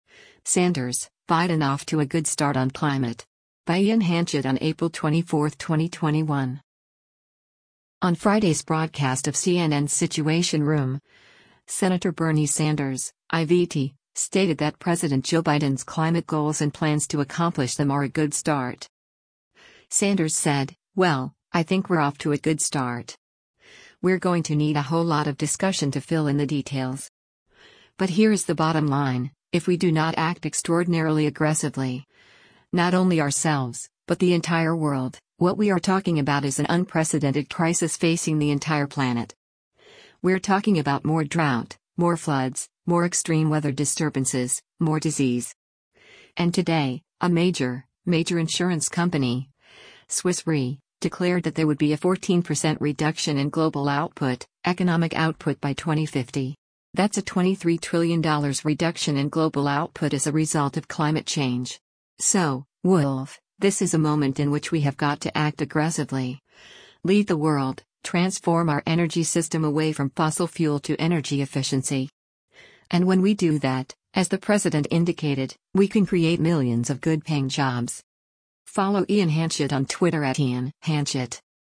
On Friday’s broadcast of CNN’s “Situation Room,” Sen. Bernie Sanders (I-VT) stated that President Joe Biden’s climate goals and plans to accomplish them are “a good start.”